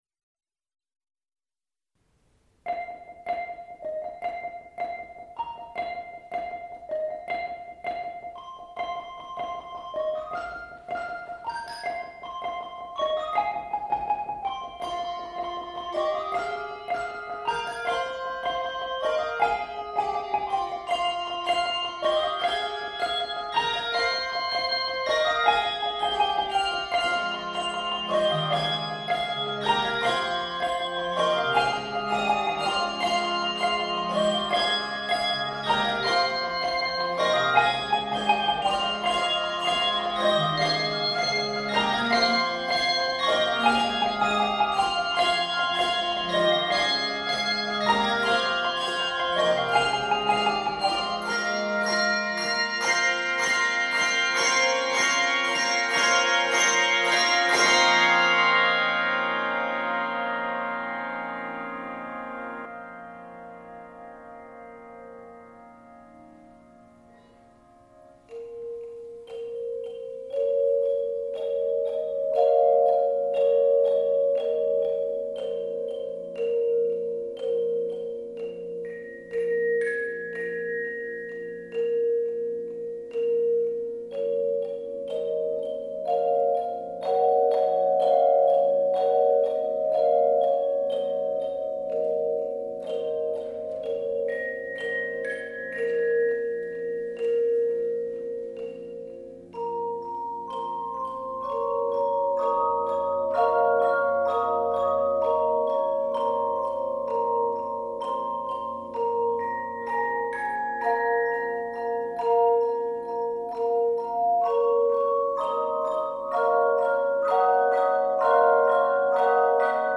Key of f minor.